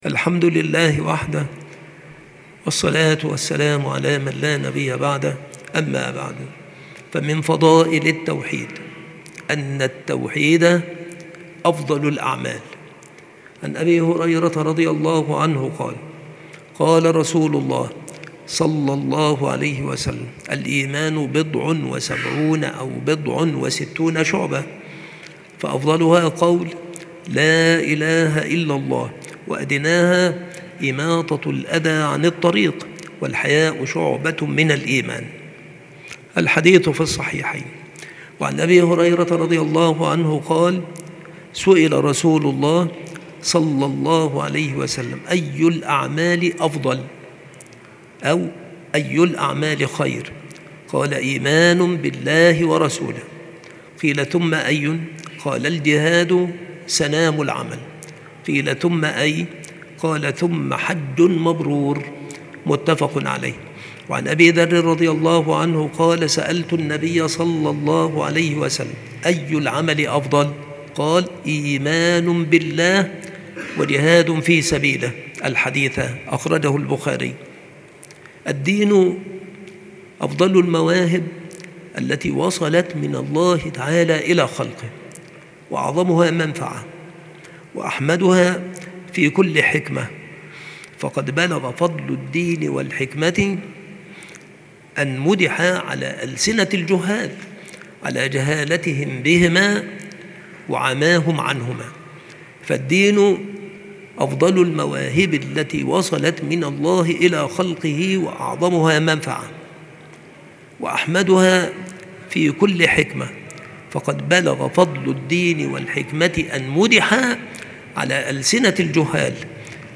تاريخ إلقاء هذه المحاضرة
بالمسجد الشرقي - سبك الأحد - أشمون - محافظة المنوفية - مصر